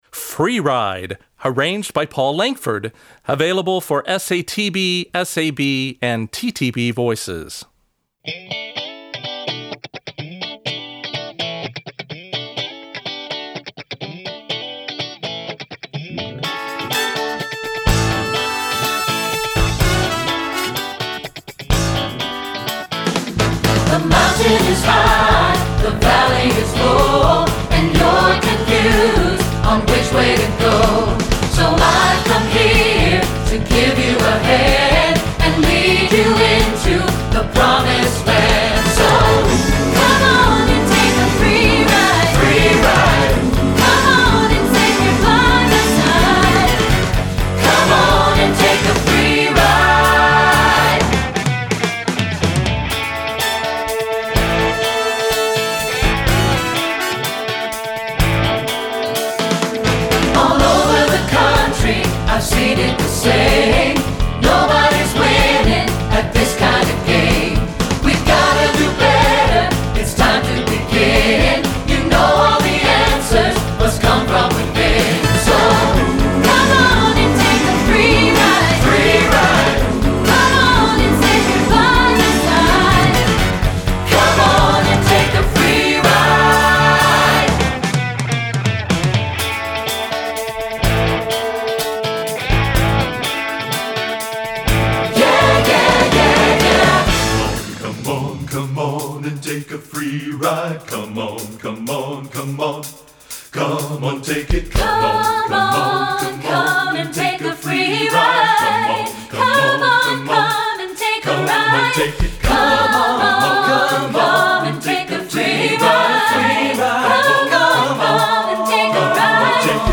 Voicing: TTB